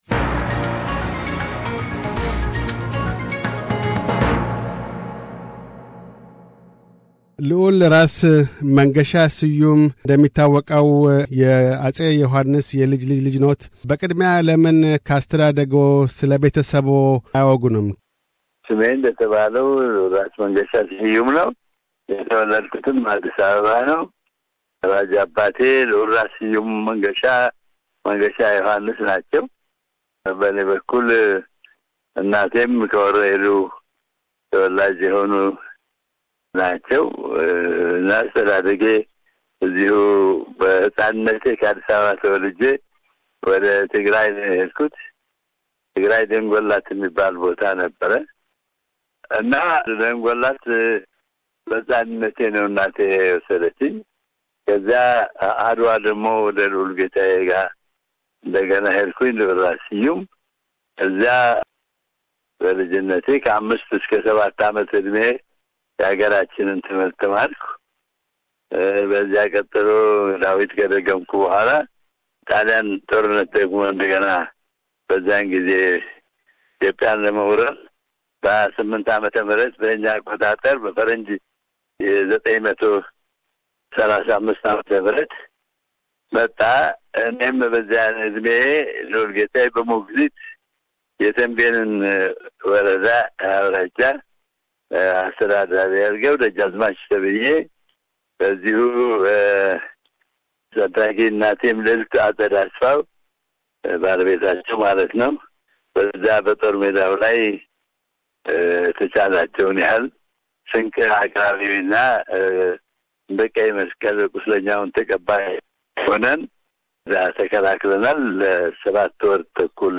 Interview with Prince Ras Mengesha Seyoum